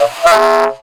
Index of /90_sSampleCDs/Zero-G - Total Drum Bass/Instruments - 3/track67 (Riffs Licks)